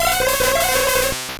Cri d'Hypnomade dans Pokémon Or et Argent.